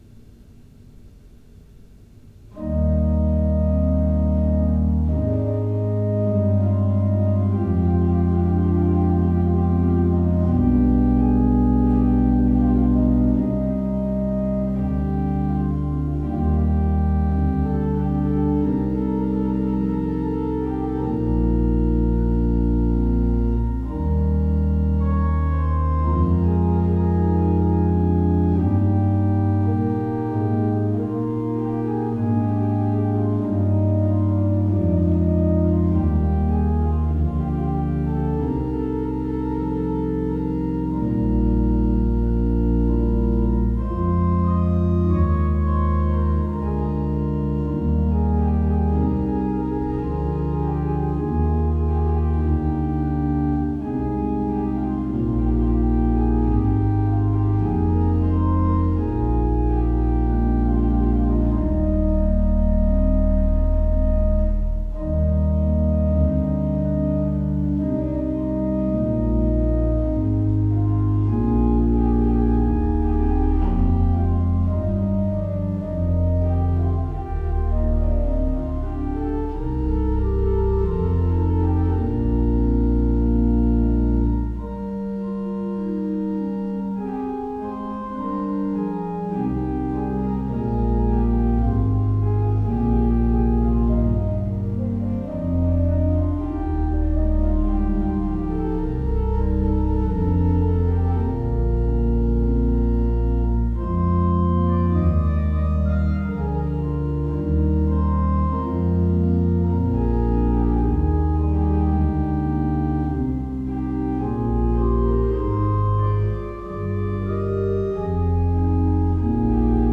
Postludes played at St George's East Ivanhoe 2024
The performances are as recorded on the Thursday evening prior the service in question and are recorded direct to PC using a Yeti Nano USB microphone..